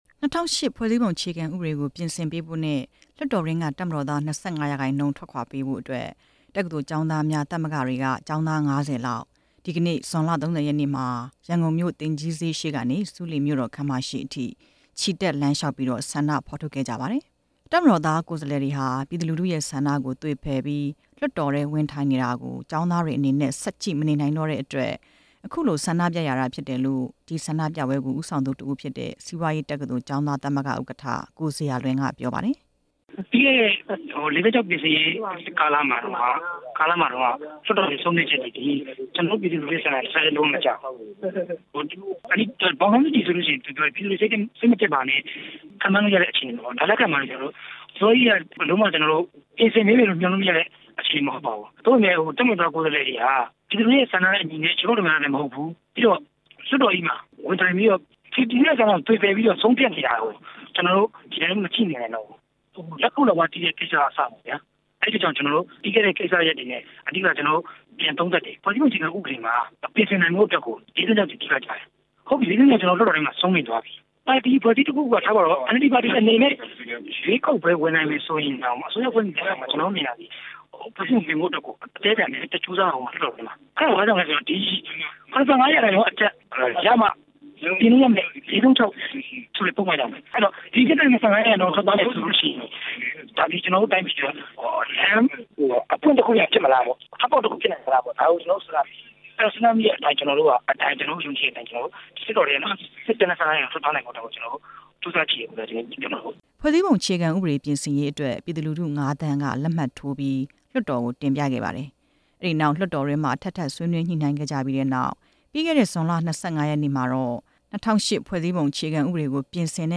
ဆန္ဒပြပွဲအကြောင်း တင်ပြချက်